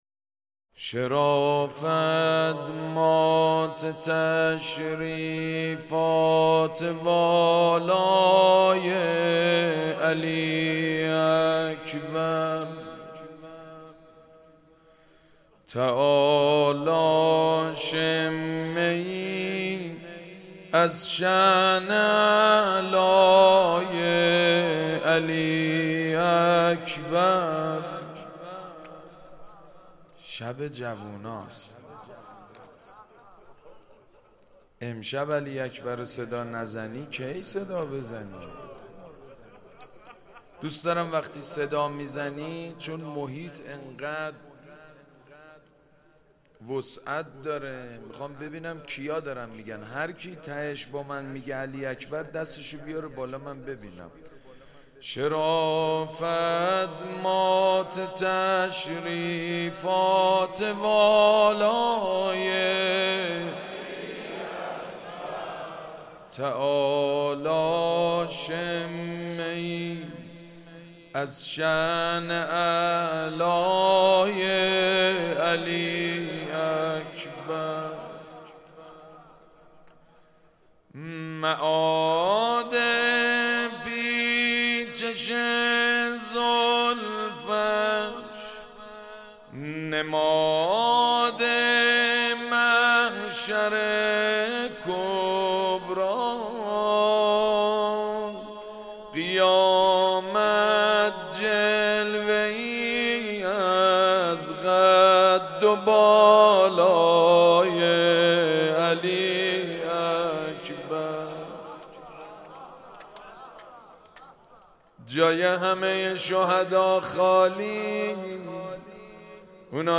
محرم1400 - شب هشتم - روضه